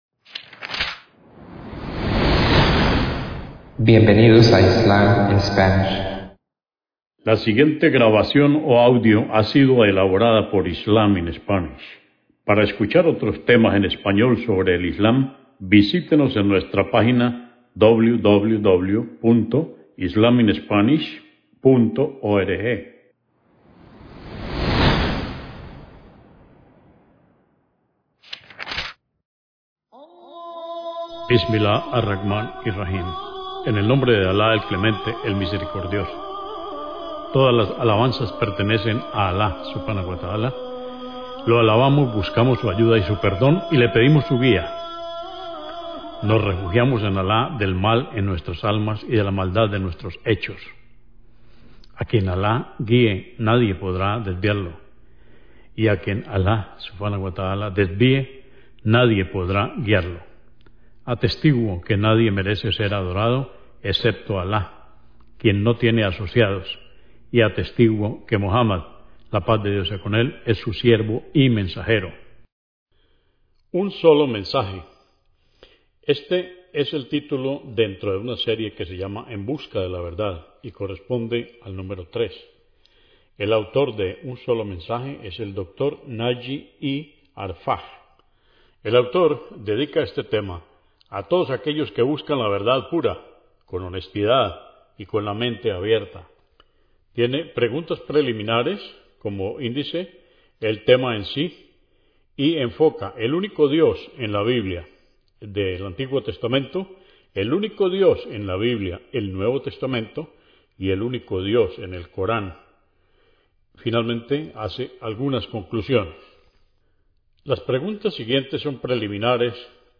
You can Litsen or Download it Un Solo Mensaje es un audiolibro para quienes buscan la verdad con sinceridad, honestidad y apertura mental. Tras la creación de Adán, un solo mensaje original se ha transmitido repetidamente a la humanidad a lo largo de la historia.